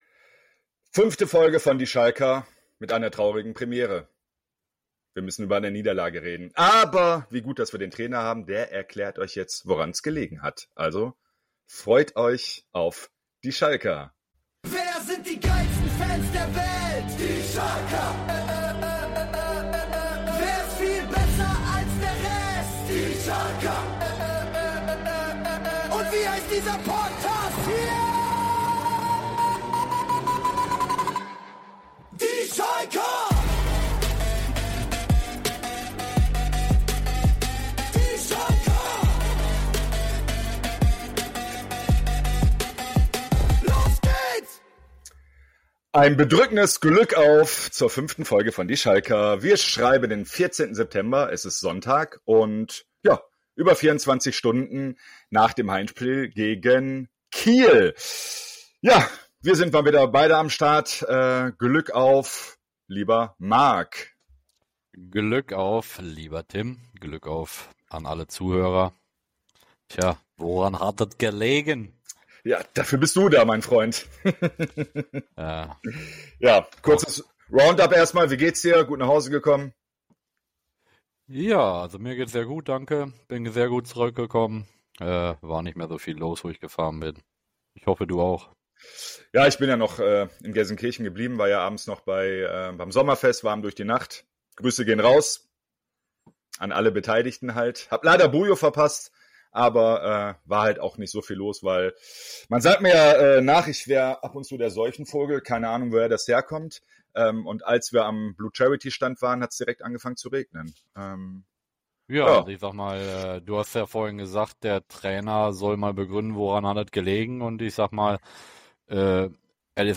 Beschreibung vor 2 Monaten Folgenbeschreibung 005 – Stolperstein Kiel Schalke stolpert daheim gegen Holstein Kiel – 0:1, nix zu holen in der Arena. Wir gehen das Spiel Stück für Stück durch, hören Stimmen von Kiel-Fans im Stadion und lassen die Pleite nochmal aufleben. Dazu gibt’s wie immer die 04 Minuten der Freundschaft: Updates zu unseren Partnervereinen Gladbach, Twente Enschede und dem 1. FC Nürnberg.